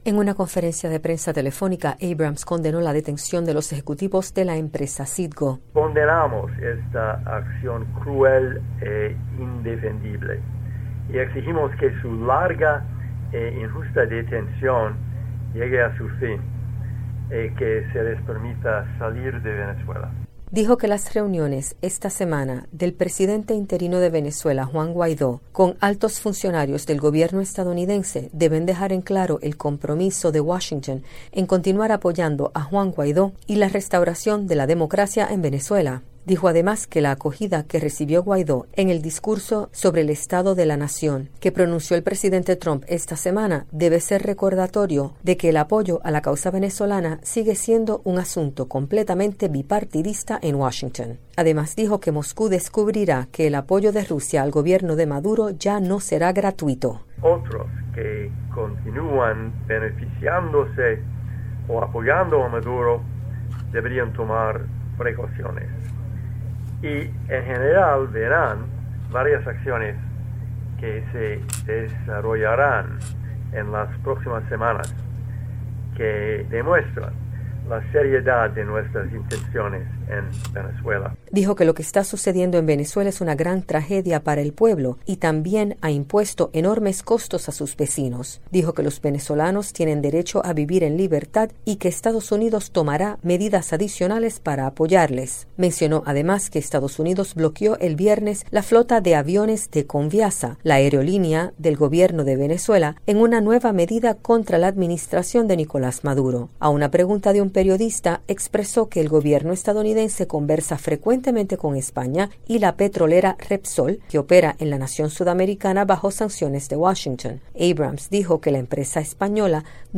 Declaraciones de Elliot Abrams, enviado especial de Estados Unidos para Venezuela